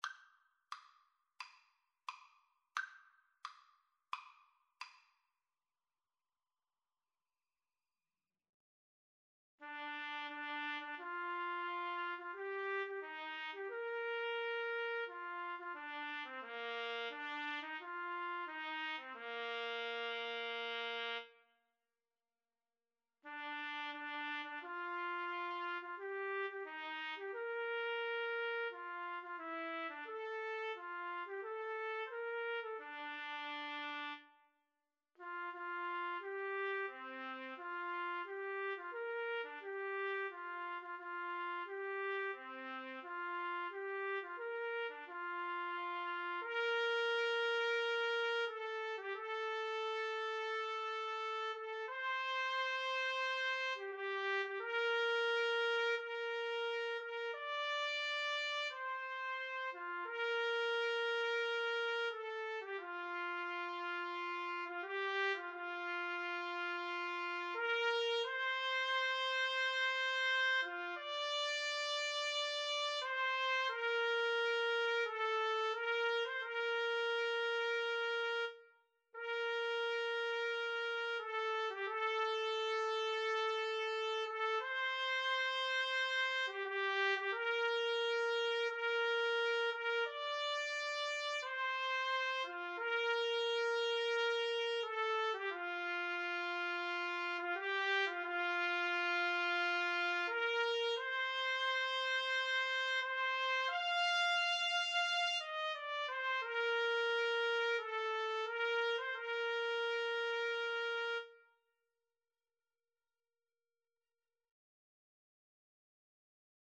Andante =c.88
Trumpet Duet  (View more Intermediate Trumpet Duet Music)